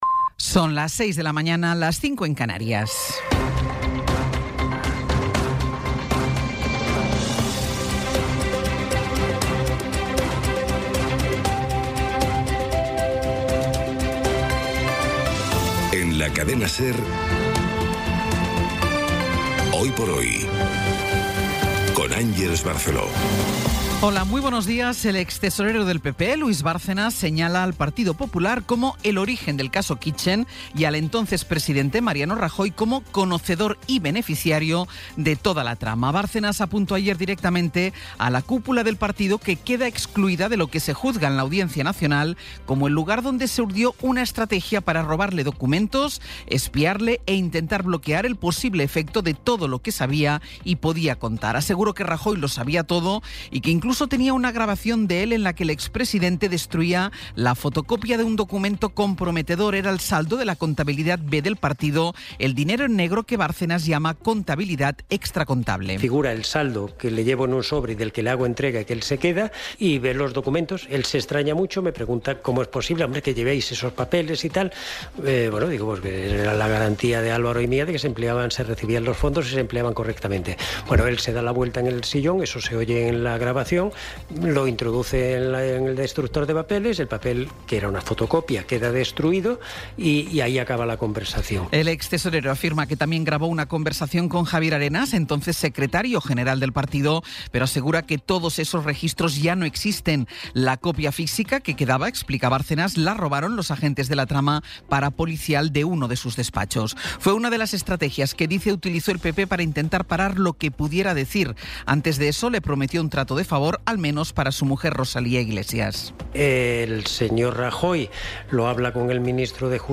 Resumen informativo con las noticias más destacadas del 21 de abril de 2026 a las seis de la mañana.